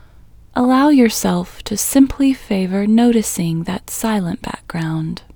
QUIETNESS Female English 12
Quietness-Female-12-1.mp3